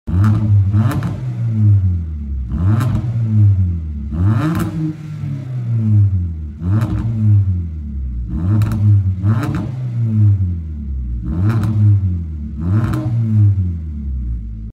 Hyundai Tucson con escape total sound effects free download
Hyundai Tucson con escape total recto, hermoso